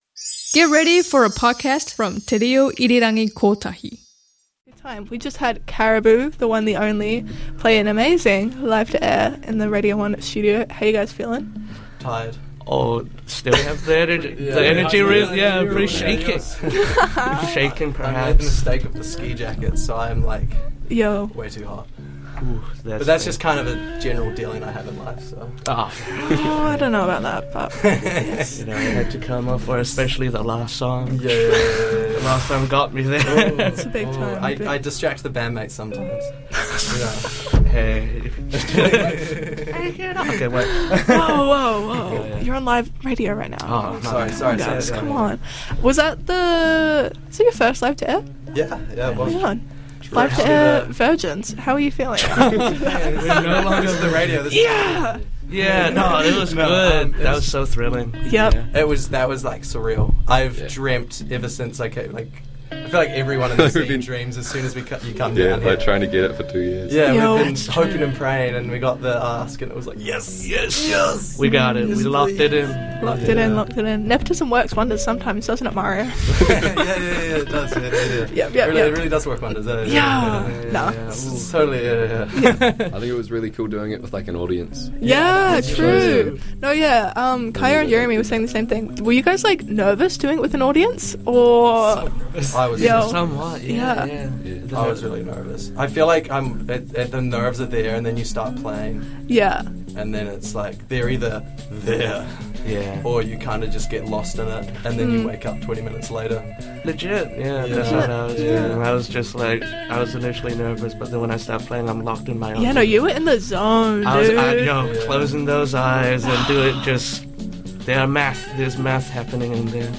INTERVIEW: Caribou after Radio One Live to Air for NZMM 2025